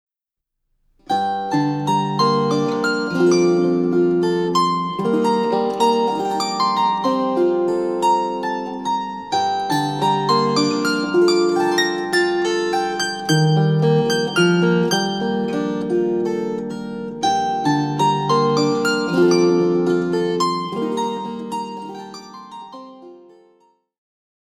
(Uchida 27 string Terz Harp Guitar)